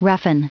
Prononciation du mot roughen en anglais (fichier audio)
Prononciation du mot : roughen